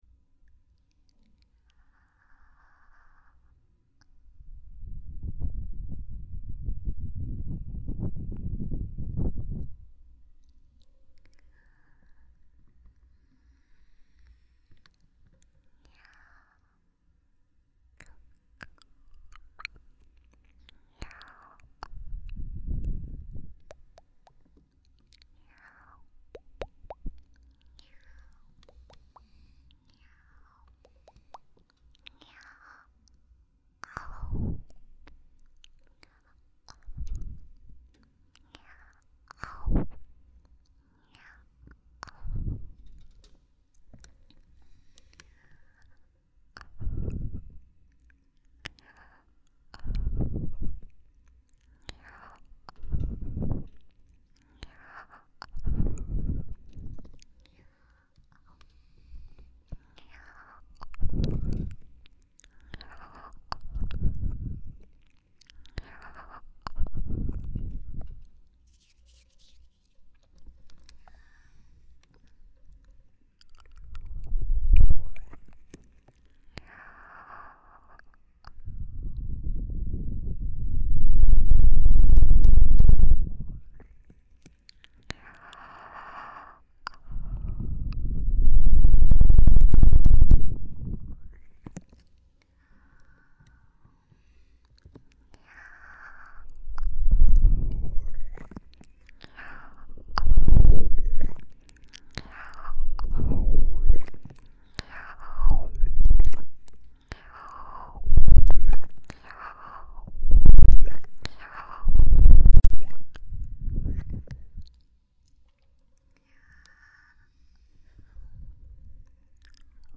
ASMR在线